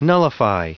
Prononciation du mot nullify en anglais (fichier audio)
Prononciation du mot : nullify